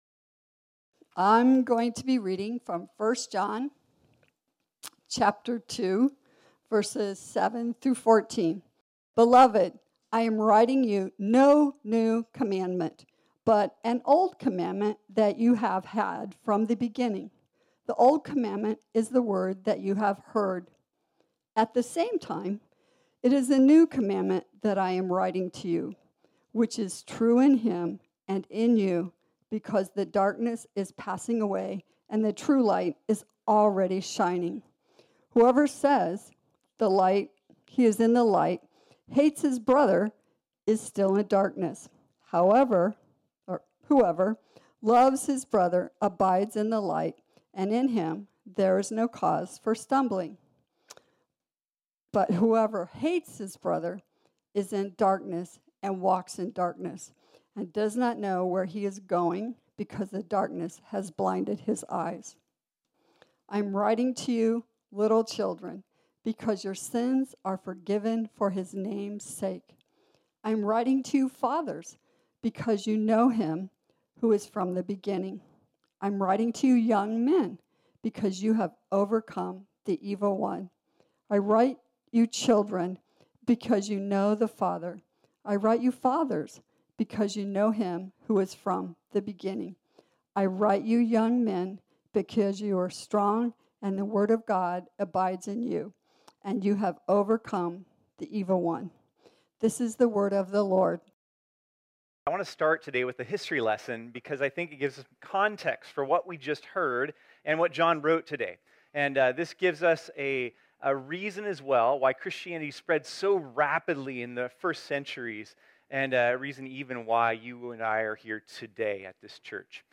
This sermon was originally preached on Sunday, September 26, 2021.